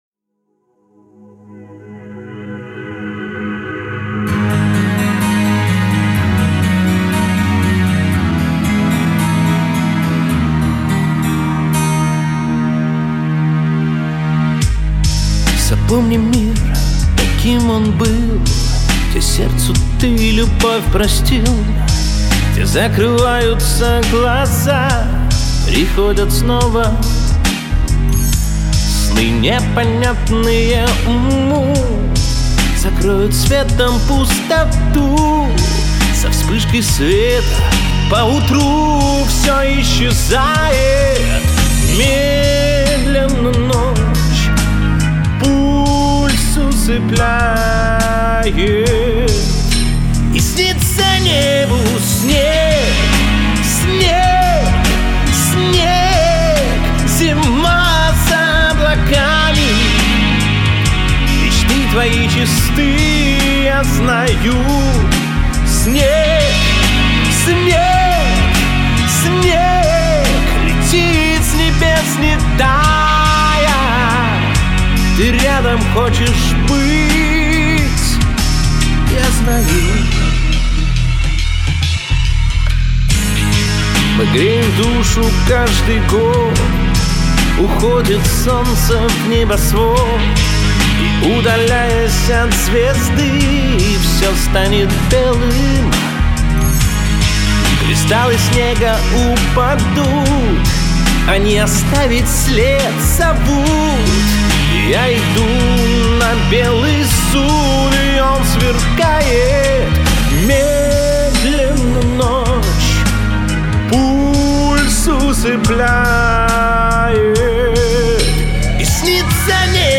Баритон